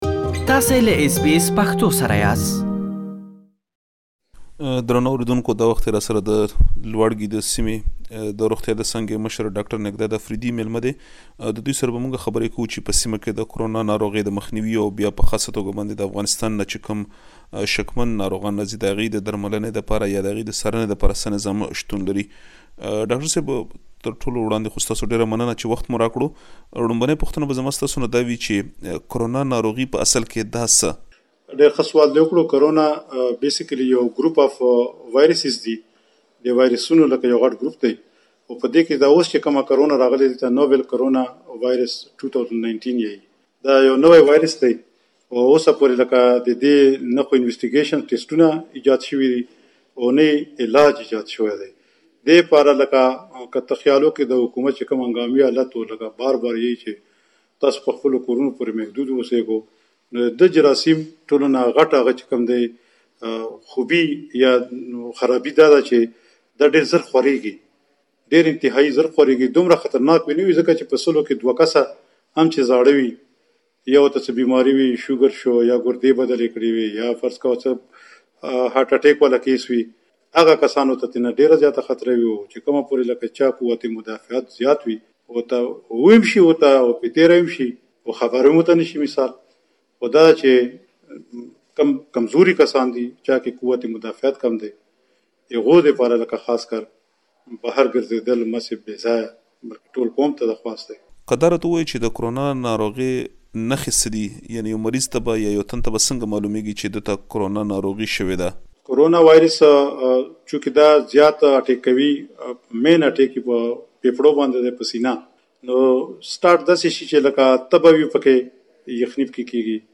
دا او نور ډير معلومات په مرکه کې واورئ.